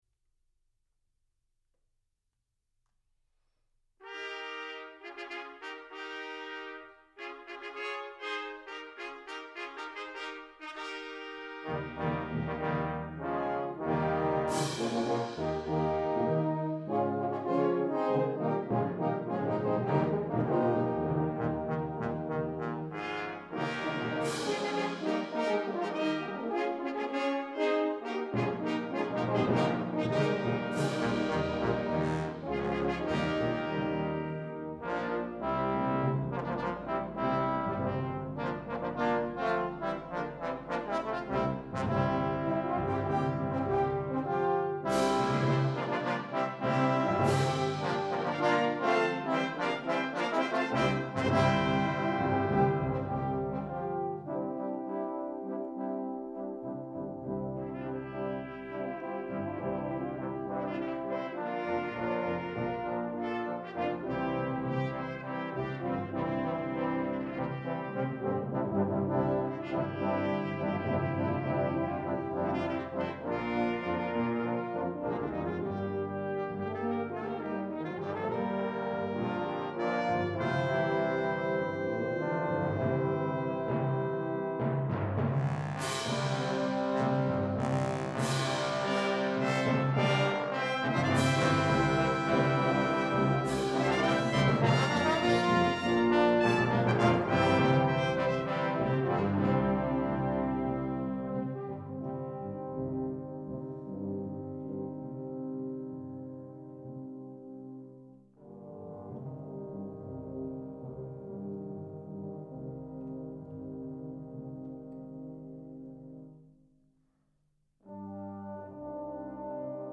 For Brass Ensemble